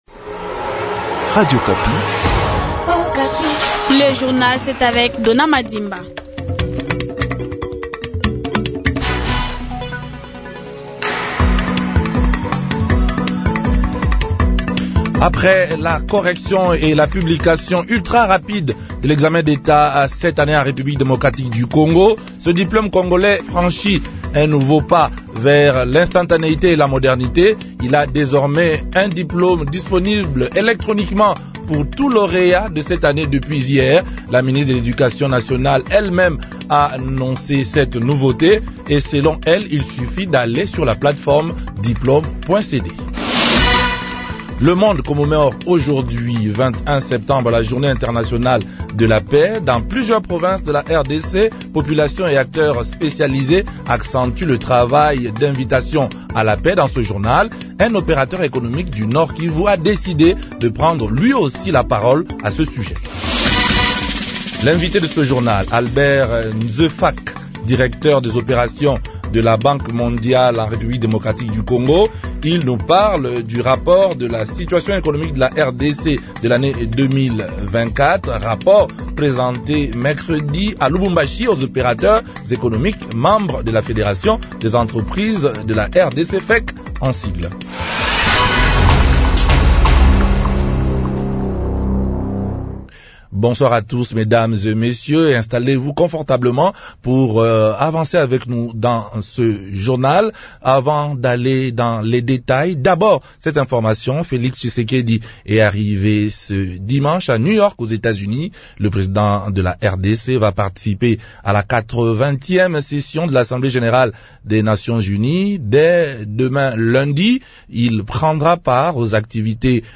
journal francais
Dans ce journal un operateur économique du Nord Kivu a décidé de prendre lui aussi la parole a ce sujet.